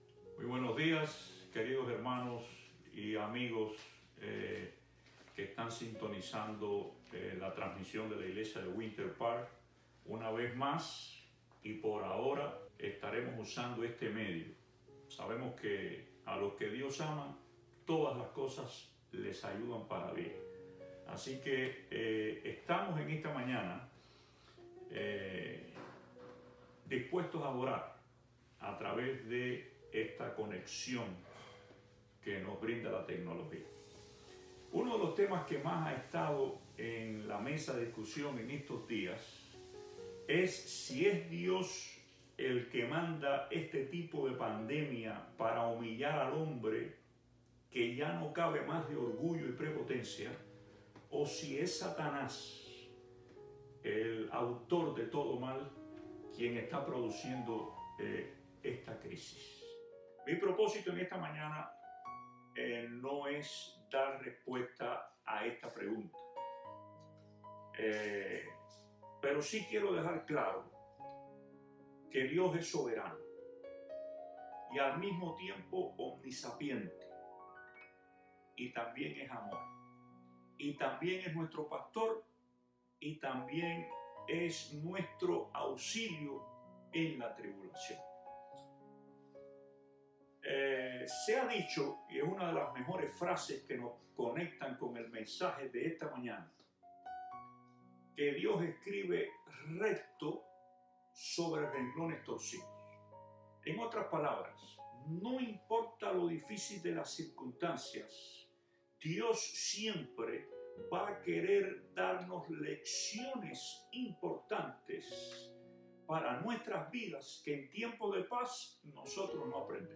Sermones - Iglesia Adventista Winter Park Spanish
Audio-Culto-Virtual-Sábado-28-de-Marzo.mp3